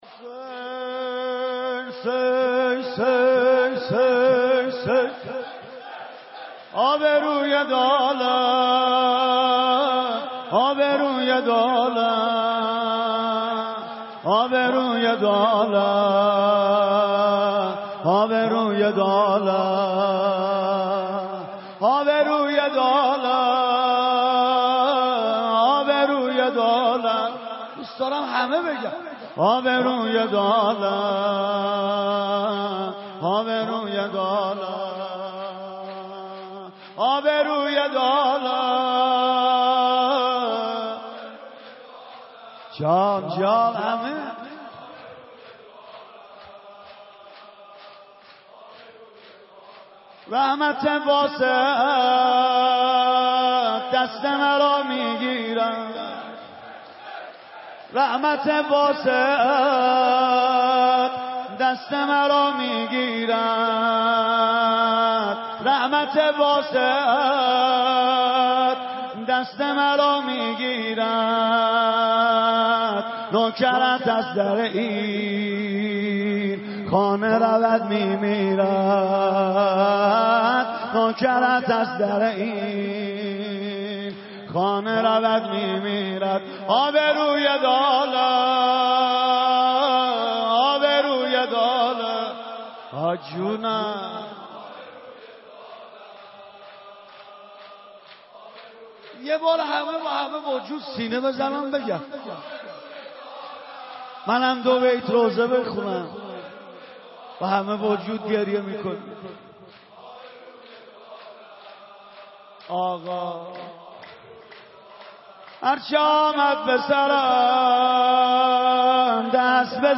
شب دوازدهم رمضان 96 - مسجد ارک - شور - حسین آّبروی دو عالم
ماه مبارک رمضان